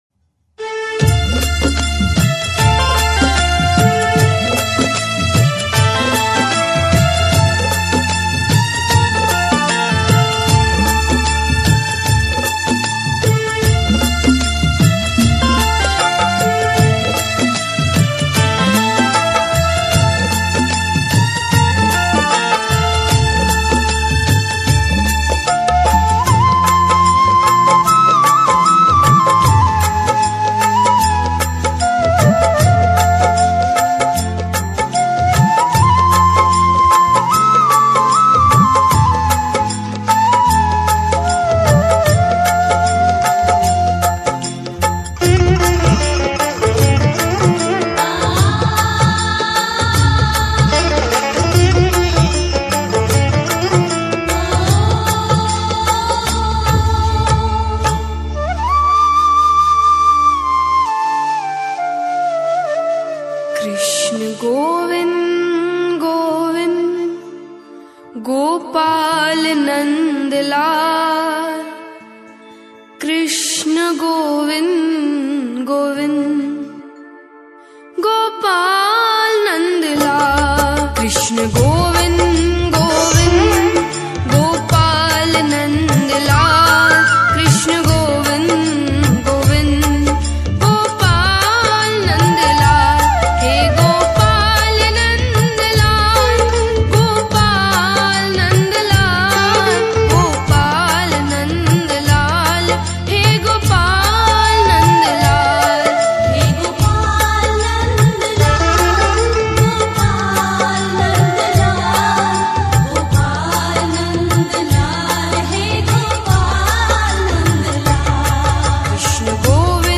Krishna bhajan dj songs